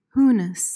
PRONUNCIATION: (HOO-nis) MEANING: noun: The essence of a person.